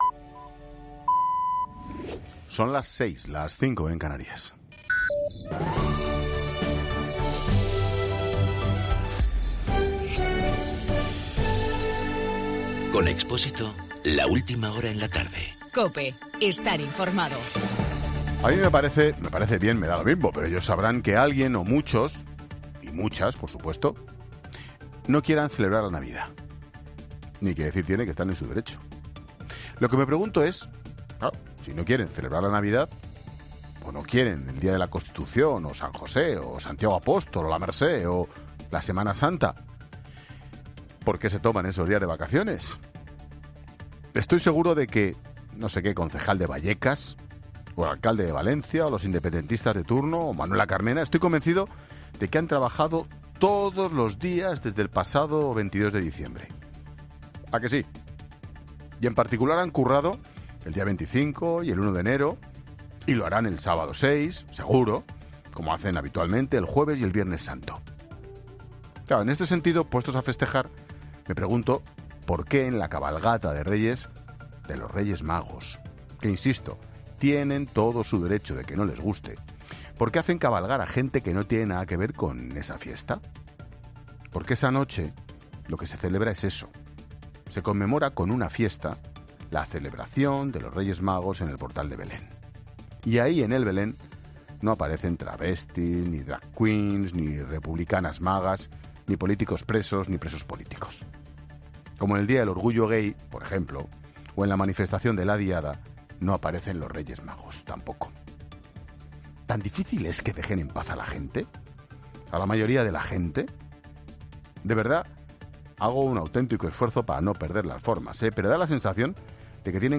AUDIO: Ángel Expósito analiza en su monólogo de las 18 horas la persecución a las tradiciones católicas en la sociedad actual.